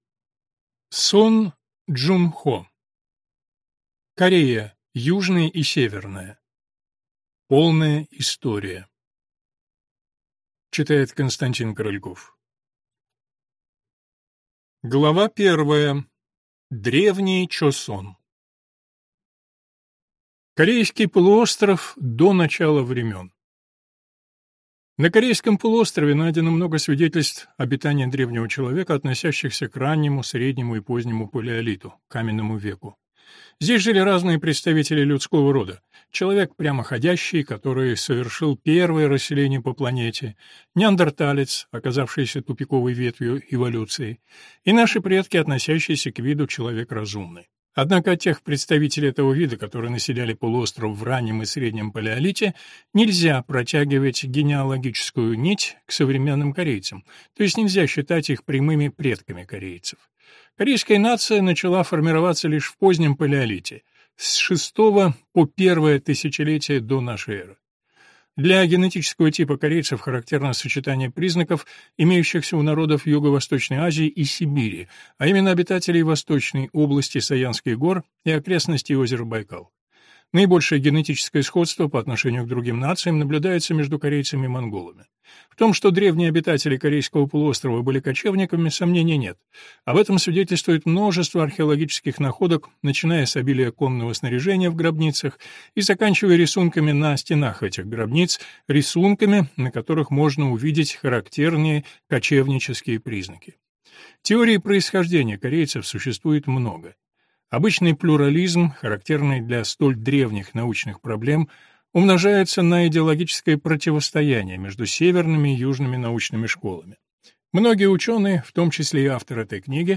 Аудиокнига Корея Южная и Северная. Полная история | Библиотека аудиокниг